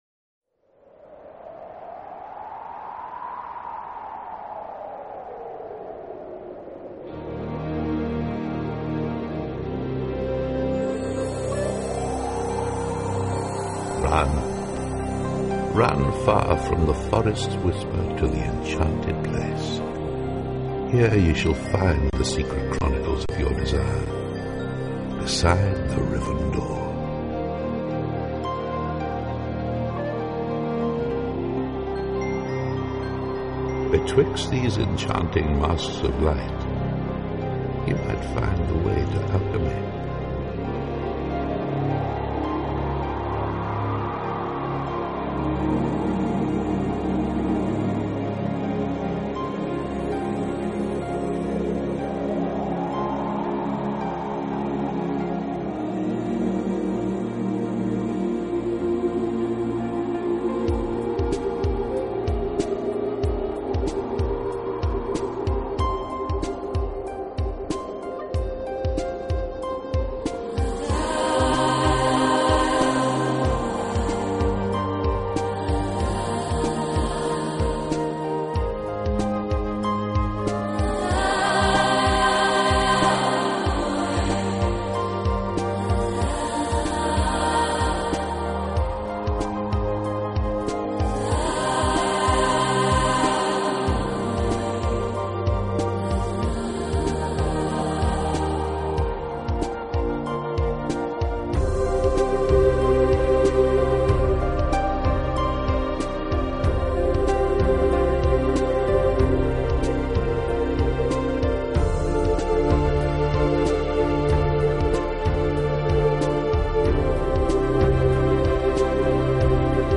Celtic/New Age